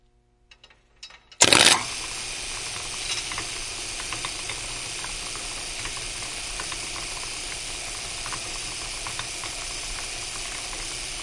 metal shop » machine metal cutter grinder rollers small close4
标签： cutter rollers close small machine metal grinder
声道立体声